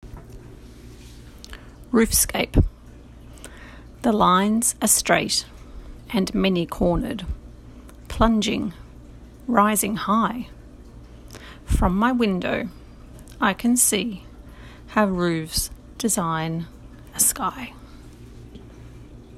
The poem in the recording below is Lilian Moore’s poem ‘Roofscape’.